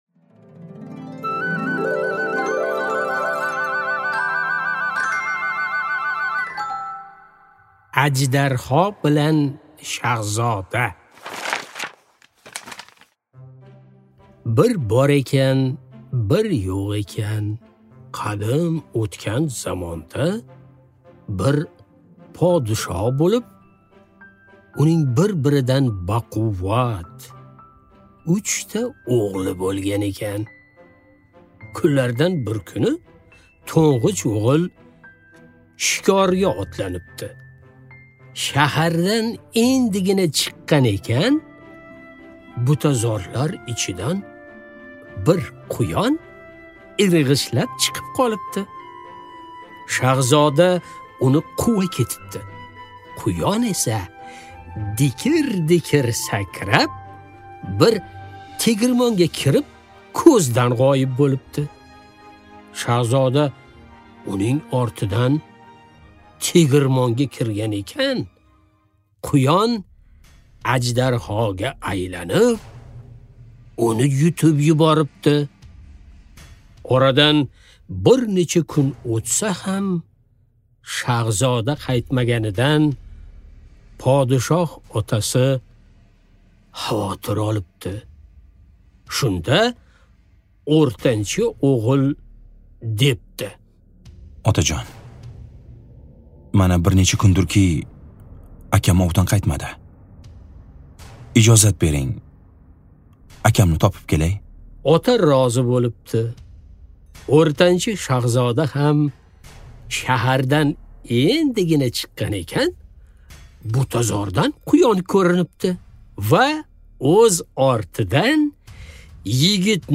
Аудиокнига Ajdarho bilan shahzoda | Библиотека аудиокниг